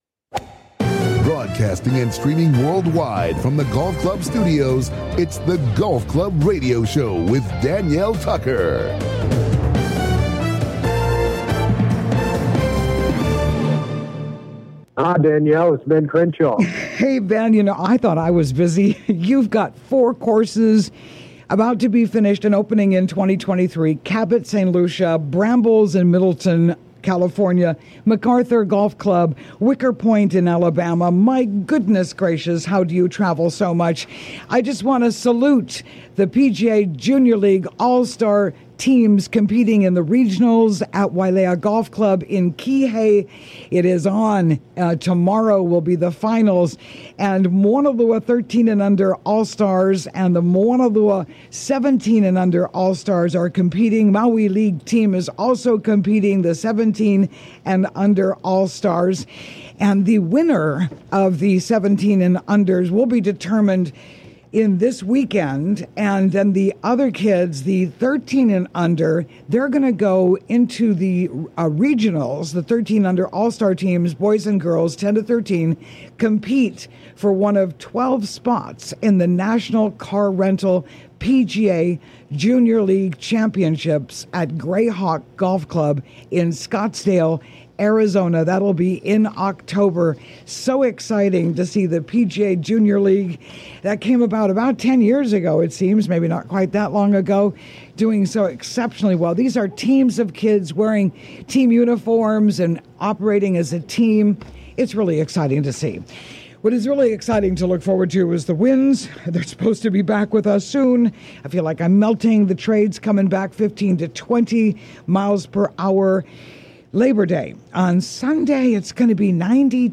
COMING TO YOU LIVE FROM THE GOLF CLUB STUDIOS ON LOVELY OAHU � WELCOME INTO THE CLUBHOUSE!